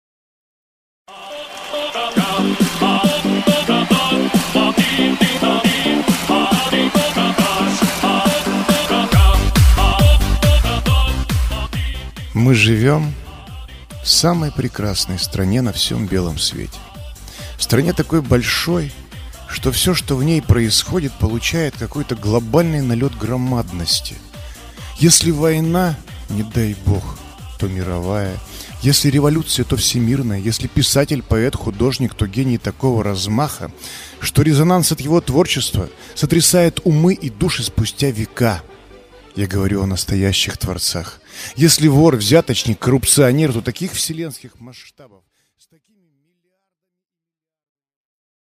Аудиокнига Телевизор | Библиотека аудиокниг